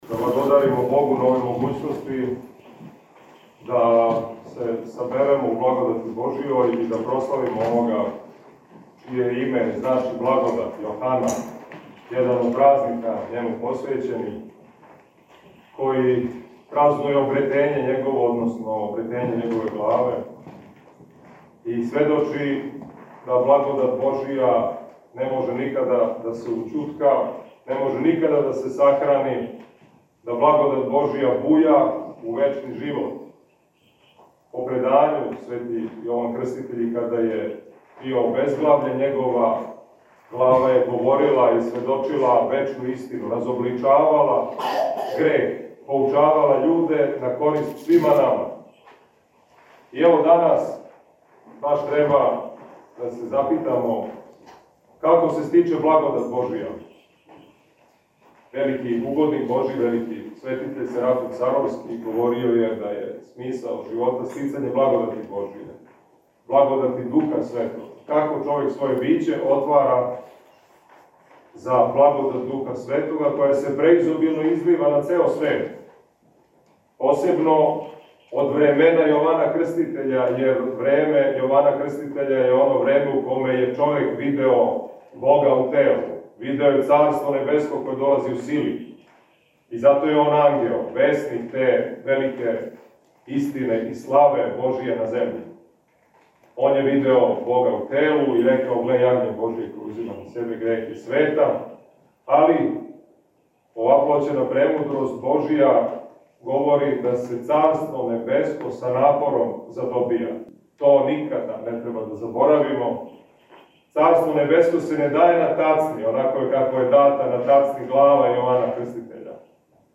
Његово Преосвештенство викарни Епископ новобрдски г. Иларион је данас, 7. јуна 2023. године, на дан када молитвено прослављамо Треће обретење часне главе Светога Јована Крститеља, свету Литургију служио у храму Рођења св. Претече и Крститеља на Централном гробљу у Београду.
Звучни запис беседе